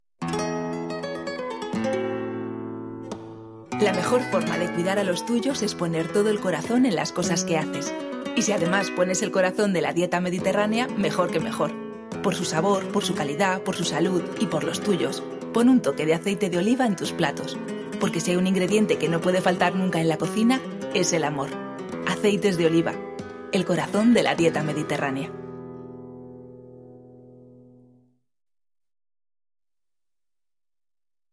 Multimedia Audio Muestra de Audio 1: Publicidad Muestra de Audio 2: Reportaje Muestra de Audio 3: No disponible Muestra de Audio 4: No disponible Multimedia Vídeo Muestra de Vídeo 1: Muestra de Vídeo 2: